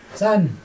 [san] number ten